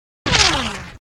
bullet.ogg